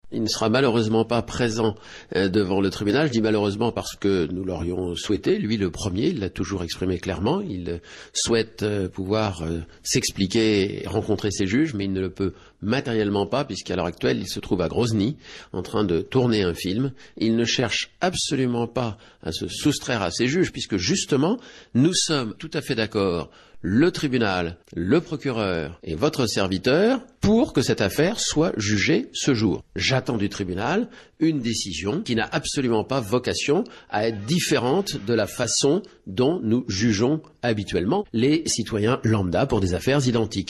Le 24 mai 2013, le procès de Gérard Depardieu pour conduite en état d’ivresse s’est ouvert sans sa présence. Au micro d’Europe 1